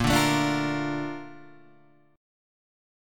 A# Major 7th